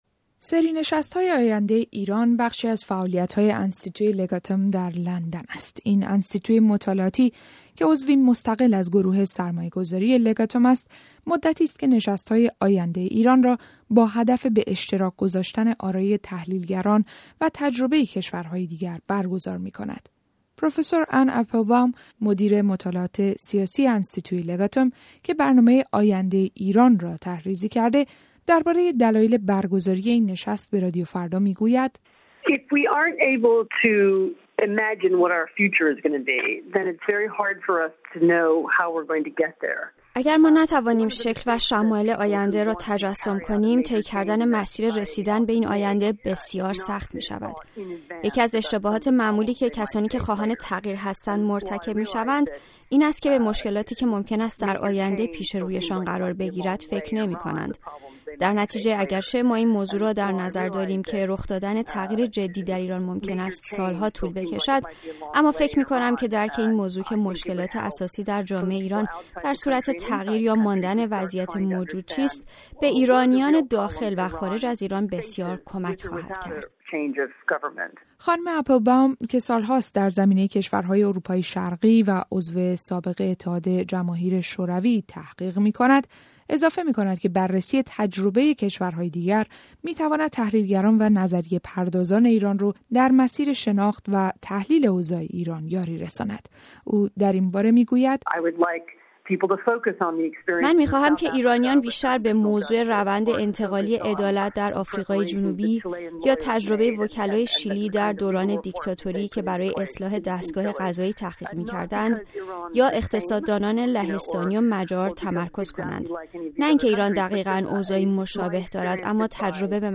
گزارش رادیویی کارگاه اصلاح آموزش در ایران در انستیتوی لگاتوم